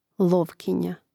lòvkinja lovkinja